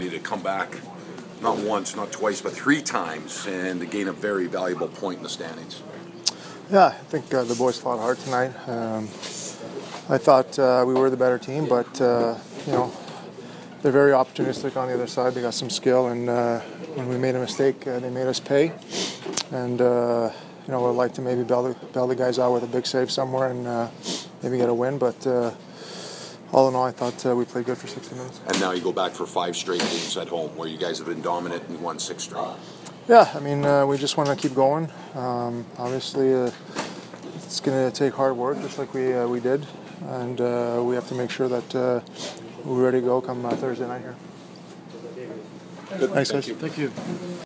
Roberto Luongo post-game 3/6